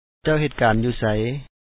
cA$w he#t kaan ju#u sa1y